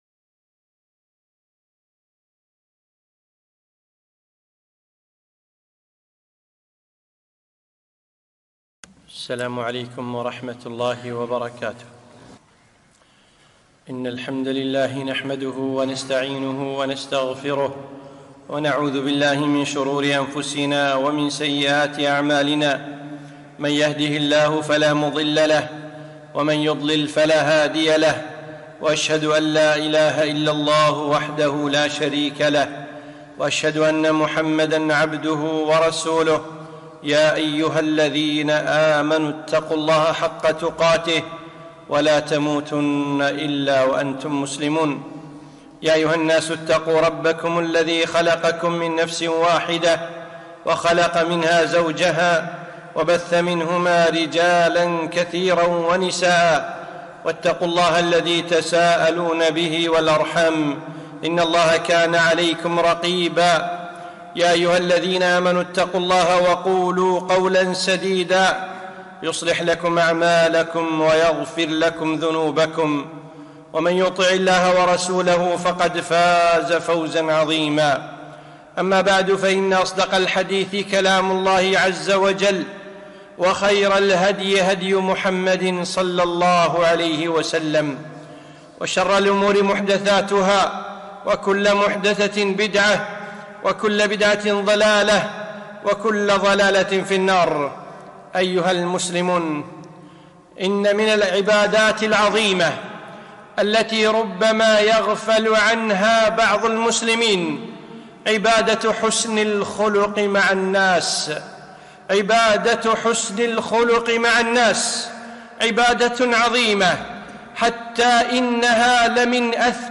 خطبة - حسن الخلق وثمراته